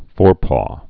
(fôr)